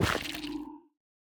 Minecraft Version Minecraft Version snapshot Latest Release | Latest Snapshot snapshot / assets / minecraft / sounds / block / sculk_sensor / place3.ogg Compare With Compare With Latest Release | Latest Snapshot